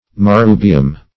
Search Result for " marrubium" : Wordnet 3.0 NOUN (1) 1. Old World aromatic herbs: horehound ; [syn: Marrubium , genus Marrubium ] The Collaborative International Dictionary of English v.0.48: Marrubium \Mar*ru"bi*um\, n. [L.]